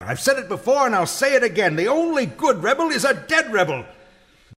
He had a nasal voice, a trait his son inherited.
2. Dark Forces: Soldier for the Empire audio drama